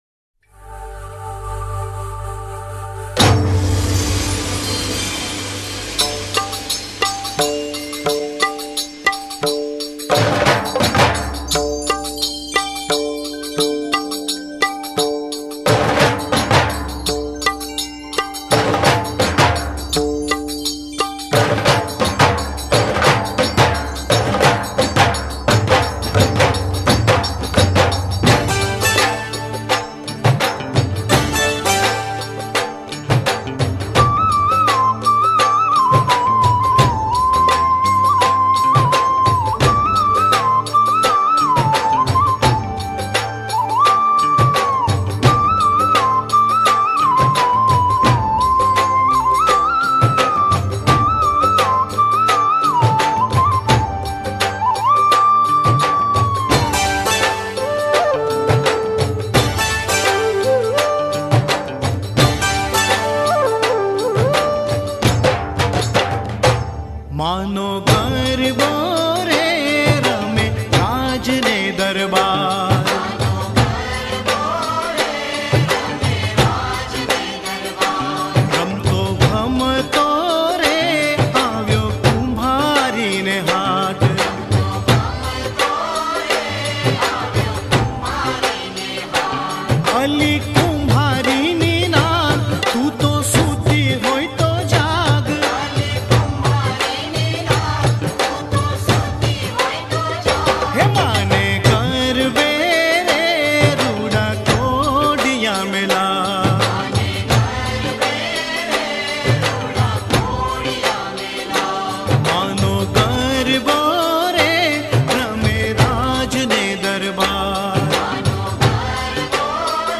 Navratri Garba Albums
Non Stop Dandiya